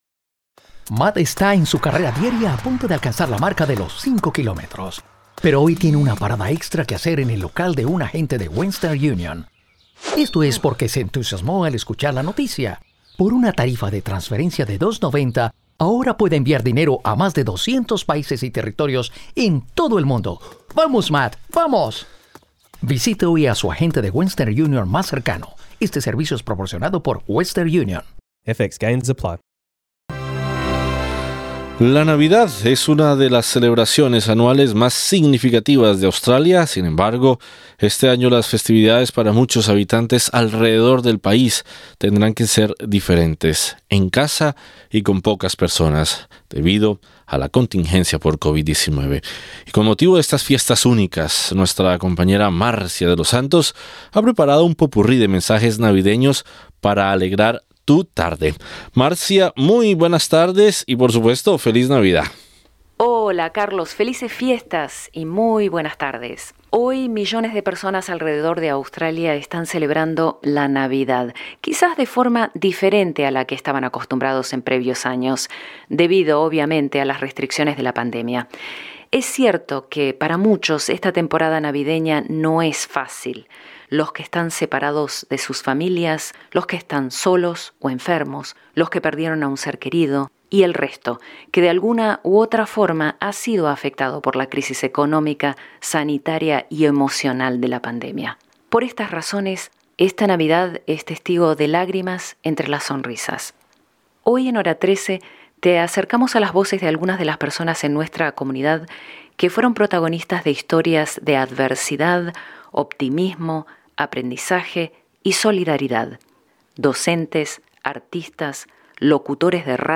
Escucha las voces de hispanos en Australia que fueron protagonistas de historias de adversidad, optimismo, aprendizaje y solidaridad; docentes, artistas, locutores de radio y trabajadores comunitarios, entre otros, que han aportado su granito de arena para hacer de este mundo un lugar mejor para todos.
Con motivo de estas fiestas únicas, escucha el popurrí de mensajes navideños de la comunidad hispana de Australia para alegrar tu día.